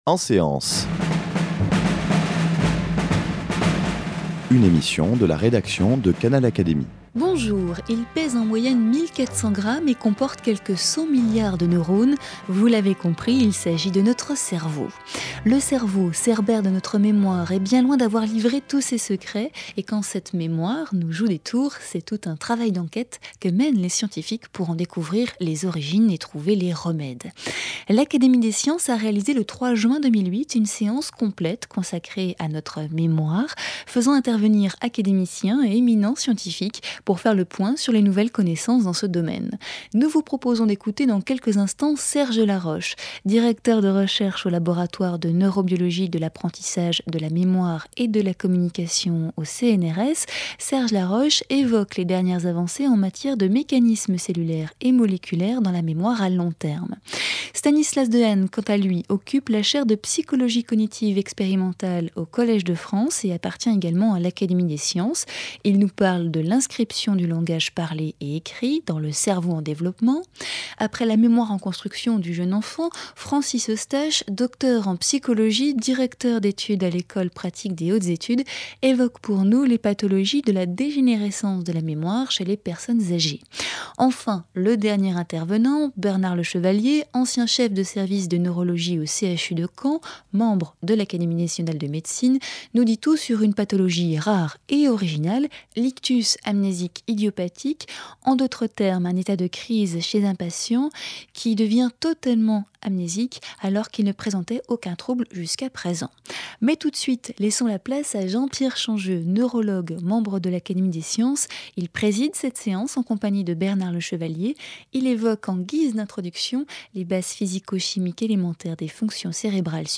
_ L’Académie des sciences a réalisé le 3 juin 2008 une séance complète consacrée à la mémoire, faisant intervenir académiciens et éminents scientifiques pour faire le point sur les nouvelles connaissances dans ce domaine